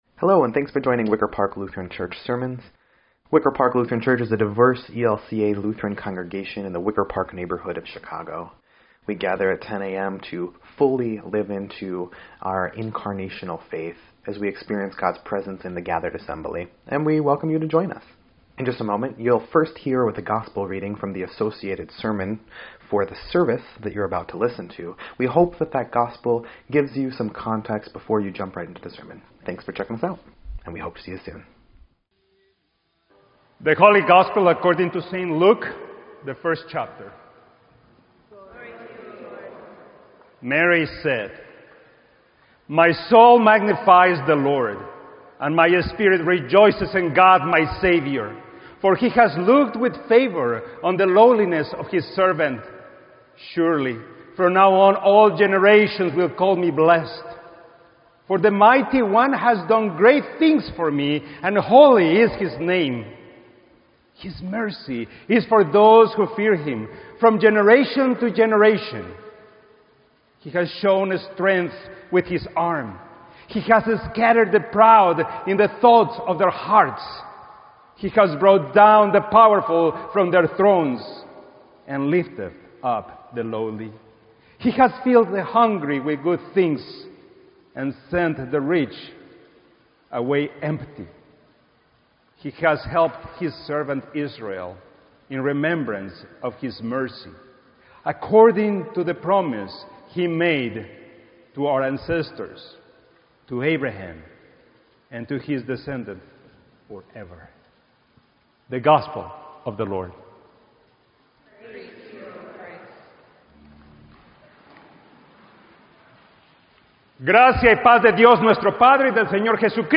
Sermon_8_20_17_EDIT.mp3